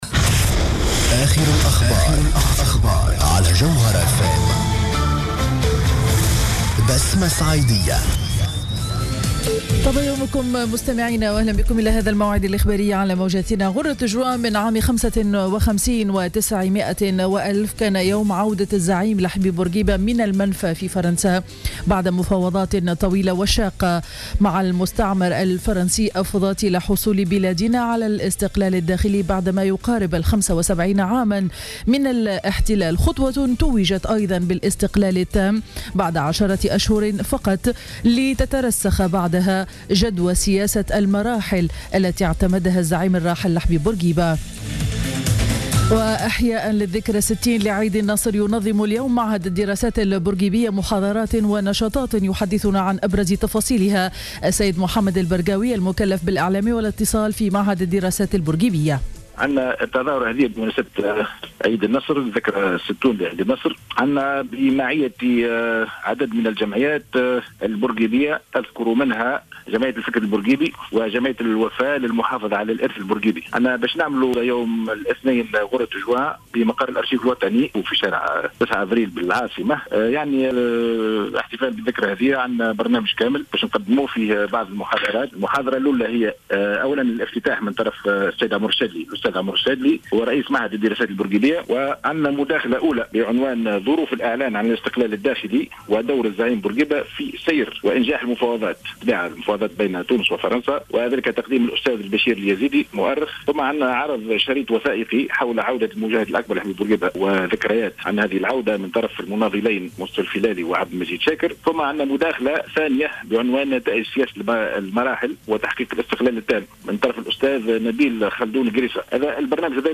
نشرة أخبار السابعة صباحا ليوم الإثنين 01 جوان 2015